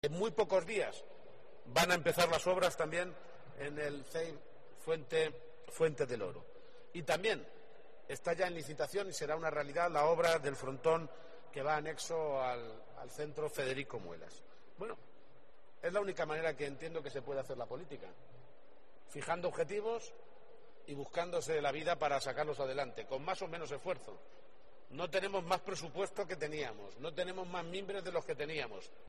Así lo ha indicado en la inauguración del nuevo pabellón deportivo del Instituto de Educación Secundaria “Pedro Mercedes” de Cuenca, una instalación de más de 1.000 metros cuadrados en cuya construcción la Junta de Comunidades ha invertido más de 546.000 euros y que, según ha indicado, “la comunidad educativa llevaba siete años esperando”.